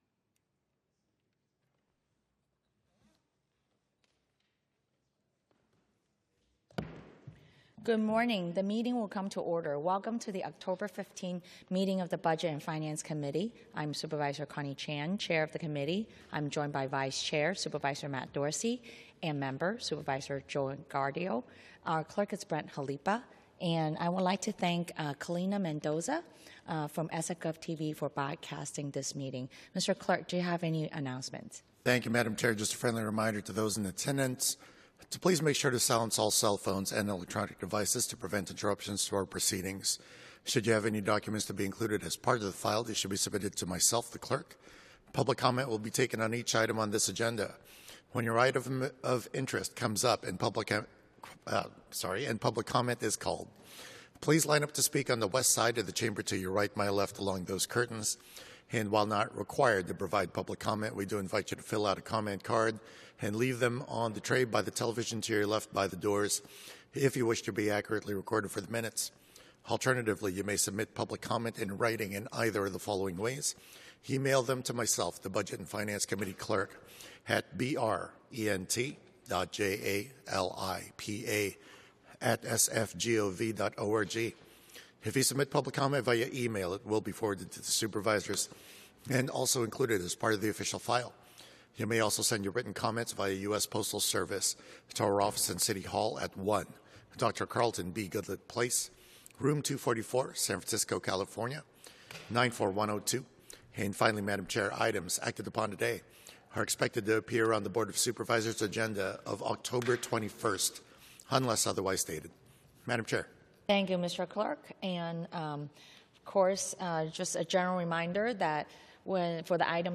BOS Budget and Finance Committee - Regular Meeting - Oct 15, 2025